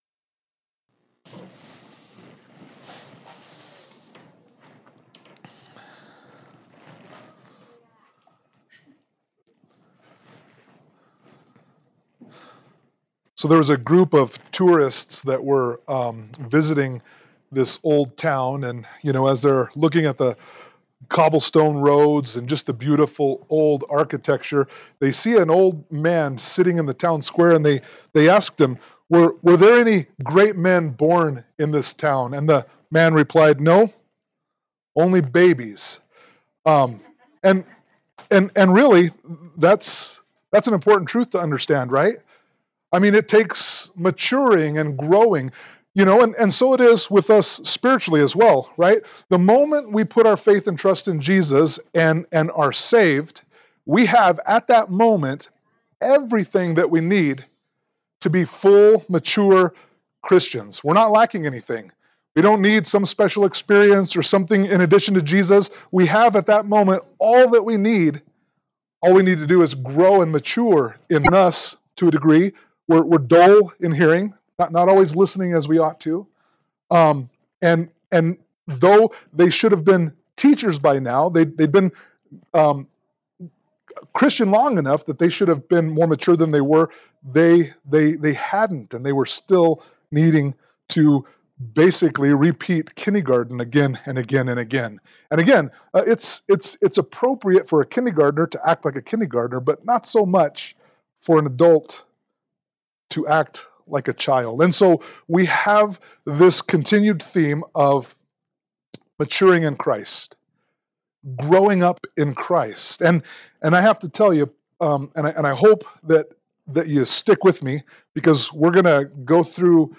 Hebrews 6:4-12 Service Type: Sunday Morning Worship « Hebrews 5:11-6:3